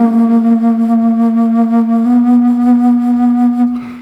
Flute 51-09.wav